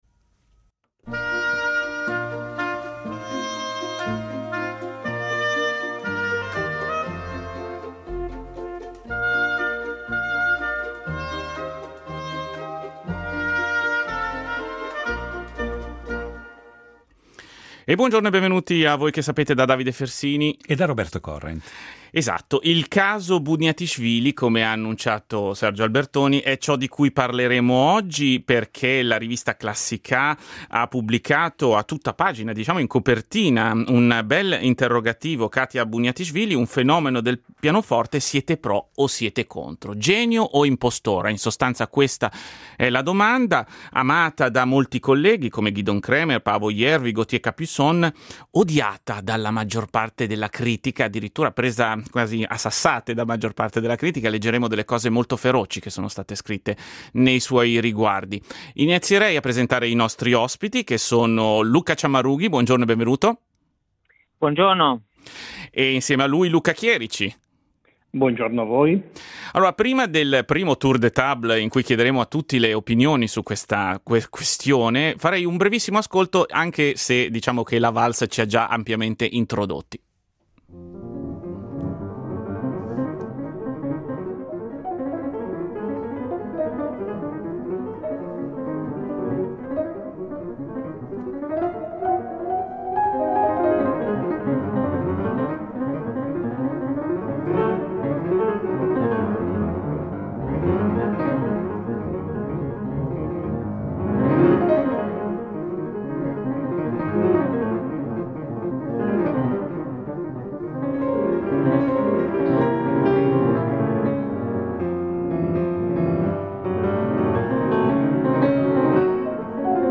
entrambi pianisti e critici musicali.